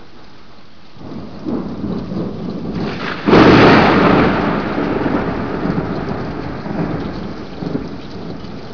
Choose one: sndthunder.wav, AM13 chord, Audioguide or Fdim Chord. sndthunder.wav